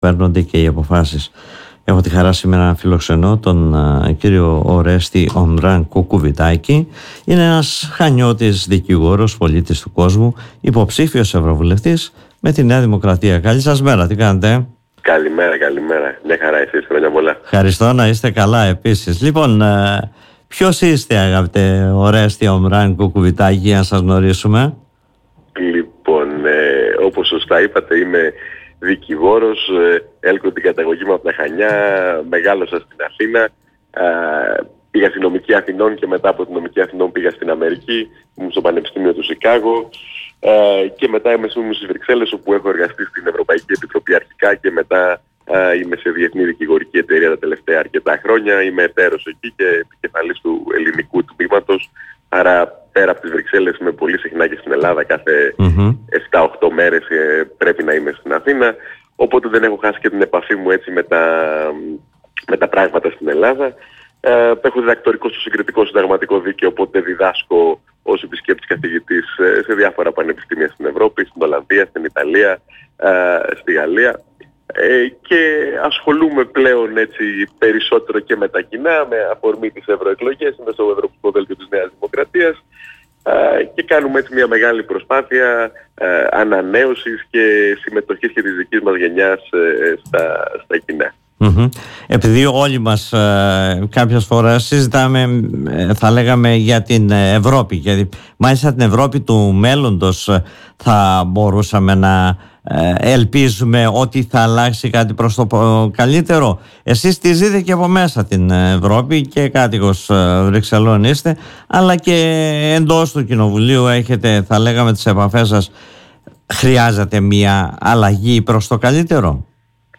μιλώντας στην εκπομπή “Δημοσίως”